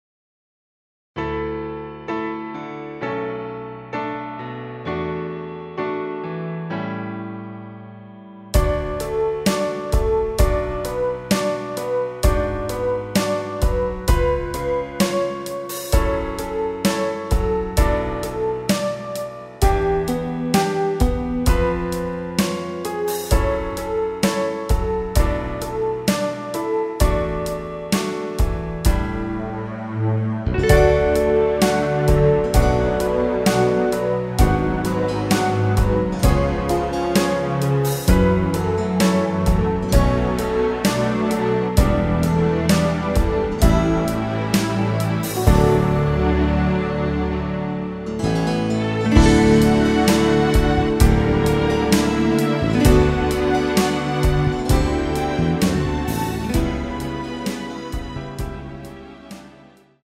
부담없이즐기는 심플한 MR
앞부분30초, 뒷부분30초씩 편집해서 올려 드리고 있습니다.
중간에 음이 끈어지고 다시 나오는 이유는